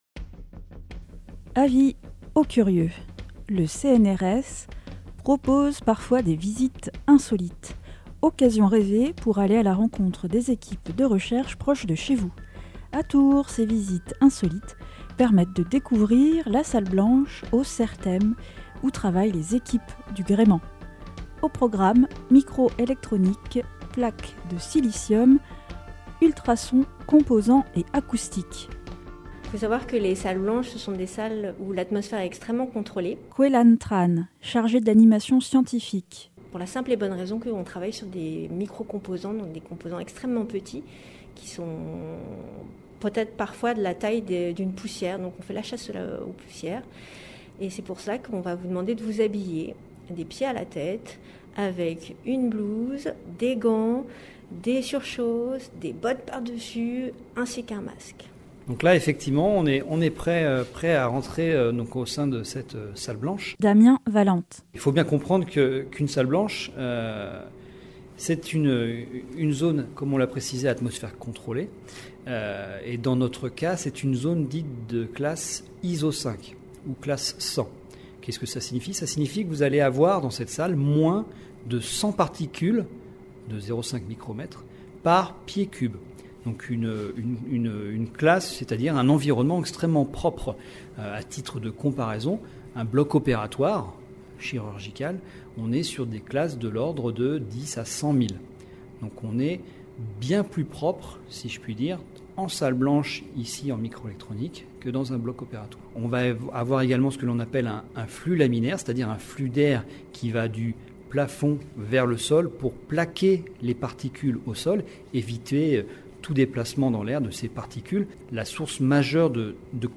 Fermez les yeux, et laissez-vous emmener dans cet univers sonore, à la découverte de la plateforme technologique qu’est le CERTEM, Centre d’études et de Recherches Technologiques en MicroElectronique, et de ses chercheurs.
2022_reportage_certem_v2.mp3